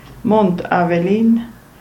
Roh-sursilvan-Munt_Avellin.ogg.mp3